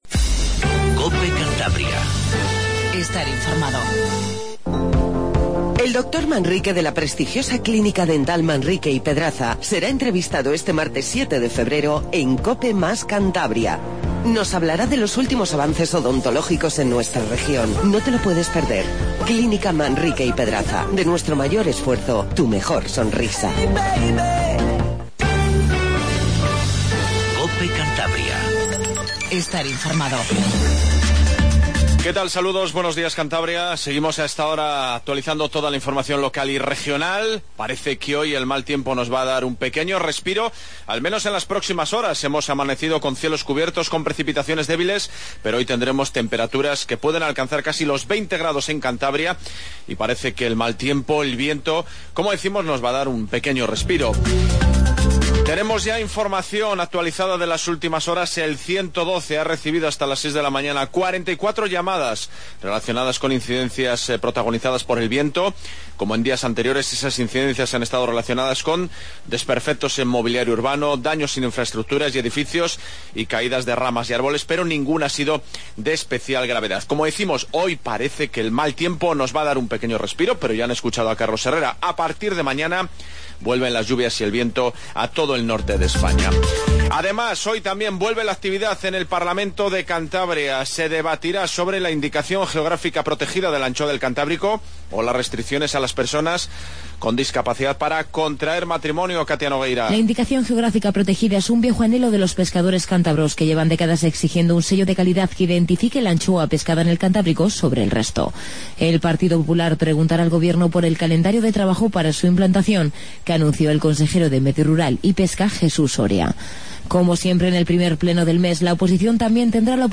INFORMATIVO MATINAL 08:20